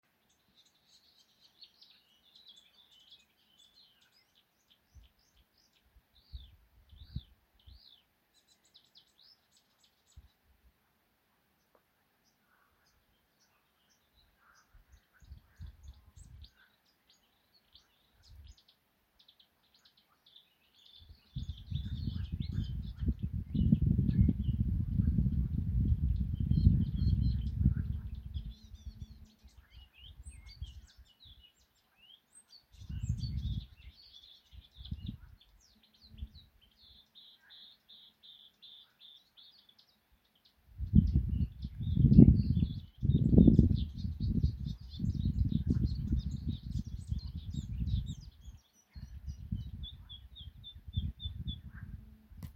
Putni -> Ķauķi ->
Purva ķauķis, Acrocephalus palustris
StatussDzied ligzdošanai piemērotā biotopā (D)